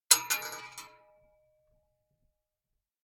Bullet Shell Sounds
rifle_metal_8.ogg